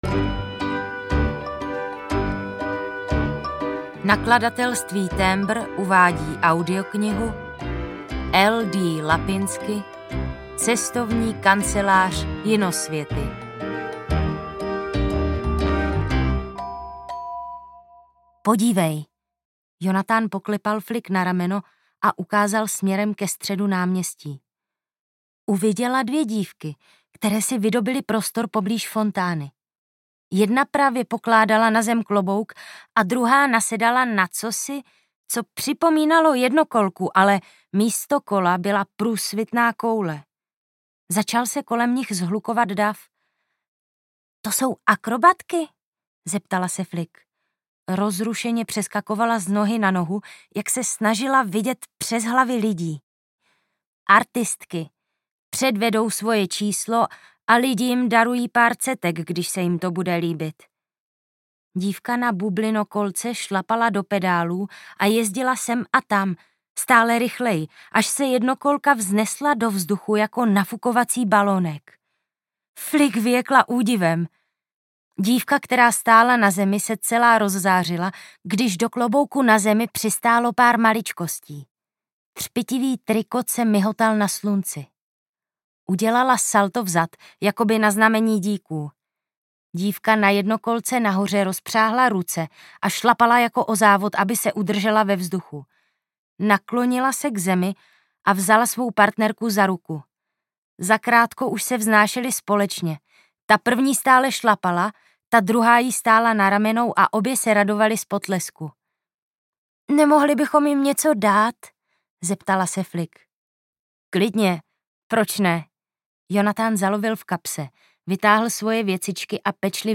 Cestovní kancelář Jinosvěty audiokniha
Ukázka z knihy